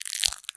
High Quality Footsteps
STEPS Glass, Walk 10.wav